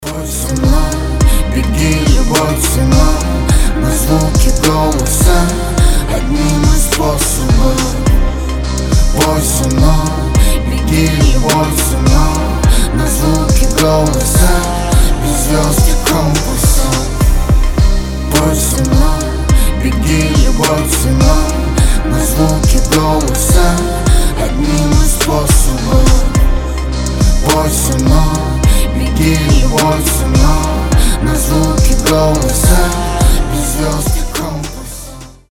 • Качество: 320, Stereo
поп
Хип-хоп
дуэт
женский и мужской вокал